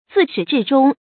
zì shǐ zhì zhōng
自始至终发音